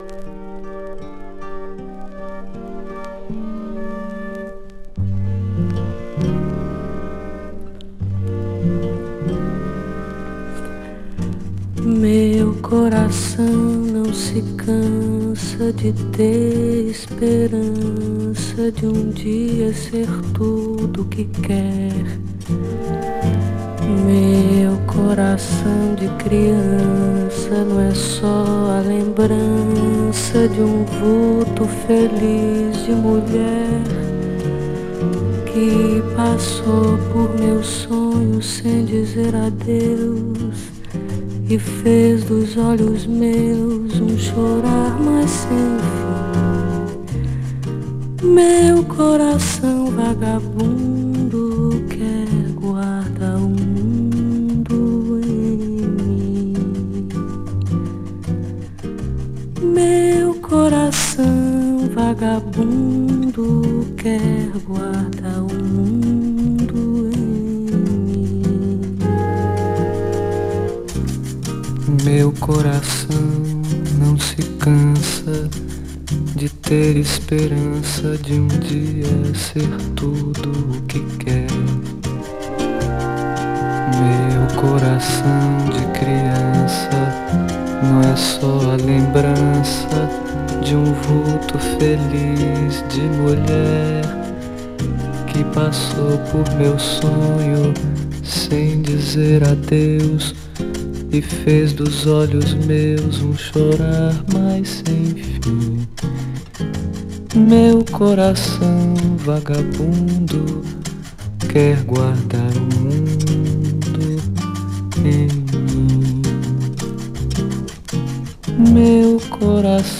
陰影のあるコード感とオーケストレーションに彩られた繊細で美しい風景。